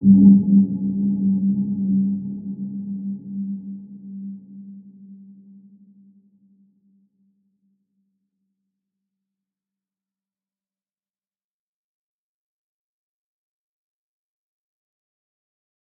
Dark-Soft-Impact-G3-p.wav